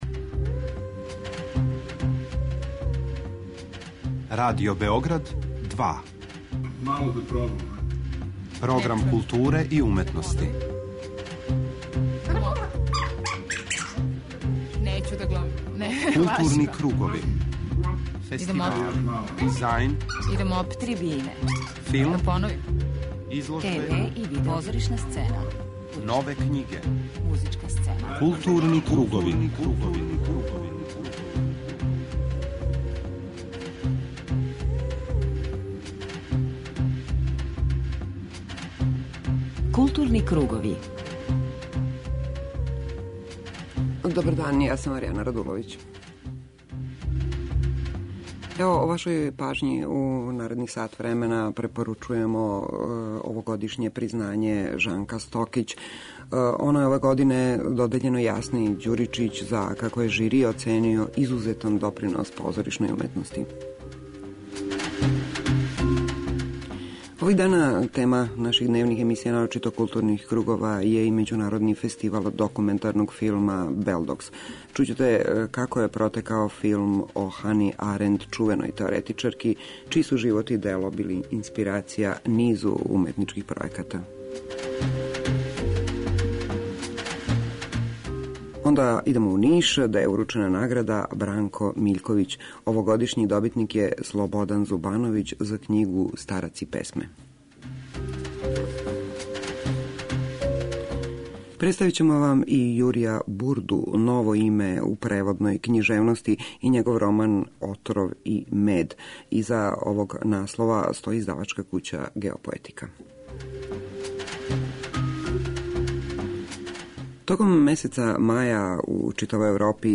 преузми : 41.11 MB Културни кругови Autor: Група аутора Централна културно-уметничка емисија Радио Београда 2.